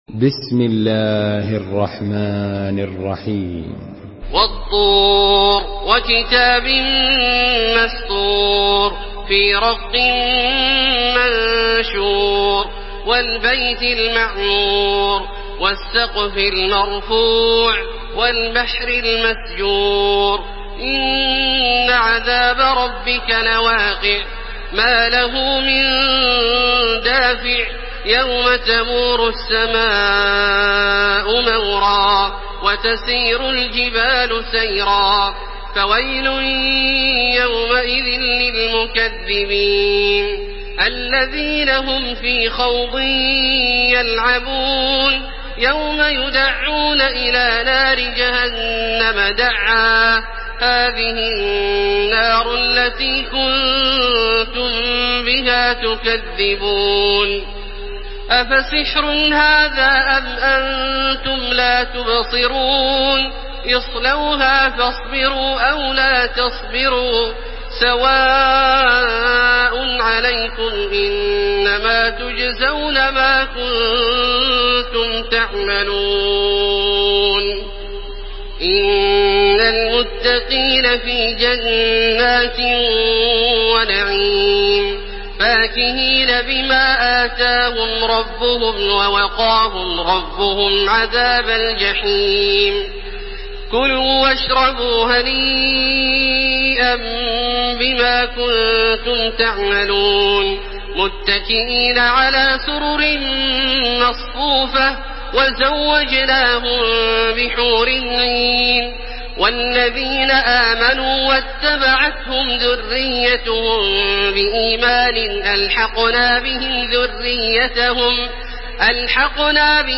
تراويح الحرم المكي 1431
مرتل